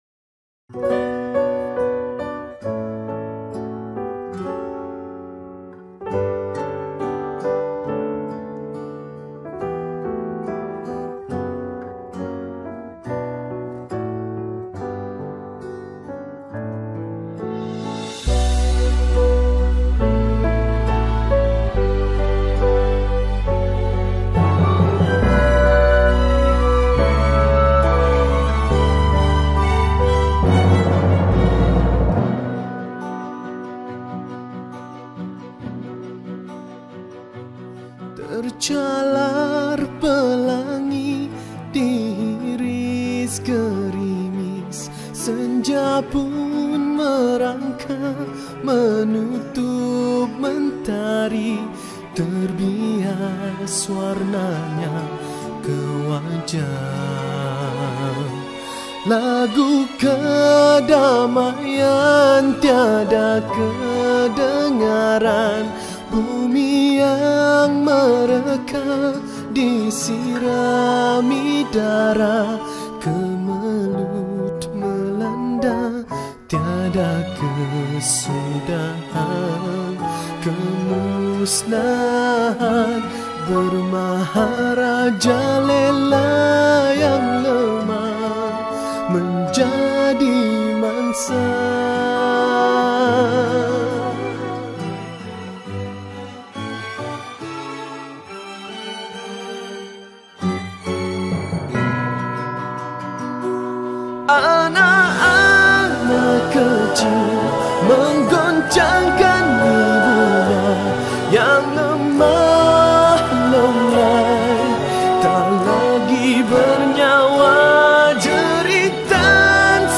Nasyid Songs
Lagu Nasyid